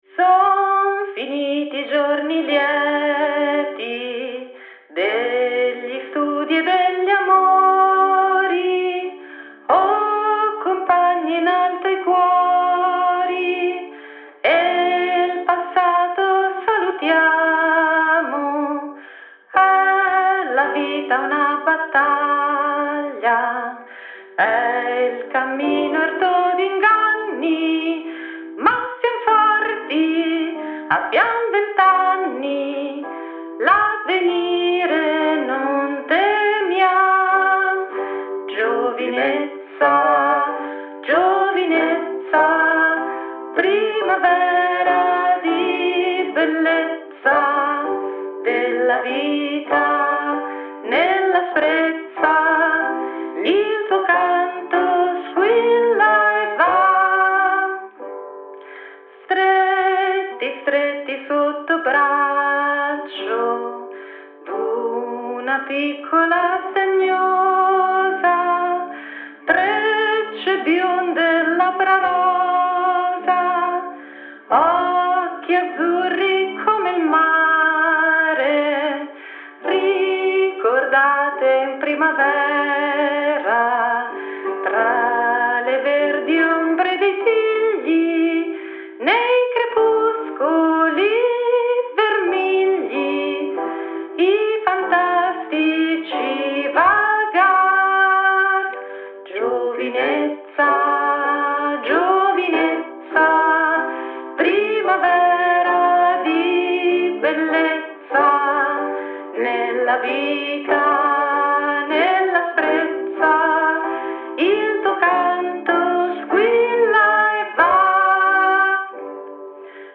La voce femminile